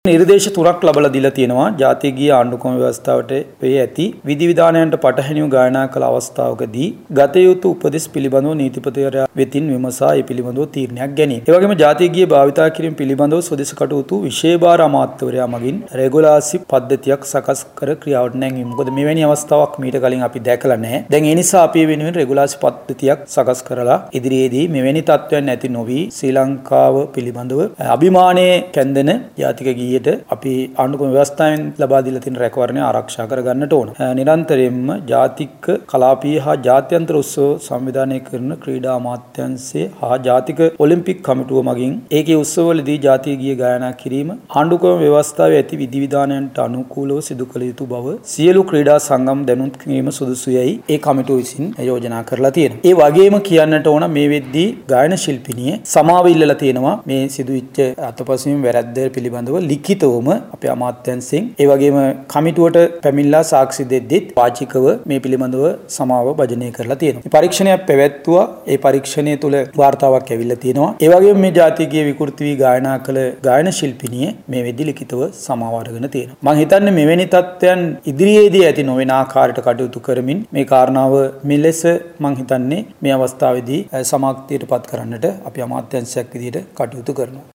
මේ සම්බන්ධයෙන් ස්වදේශ කටයුතු රාජ්‍ය අමාත්‍ය අශෝක ප්‍රියන්ත මහතා අදහස් පල කළා.